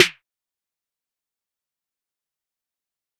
Snare 3.wav